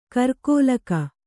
♪ karkōlaka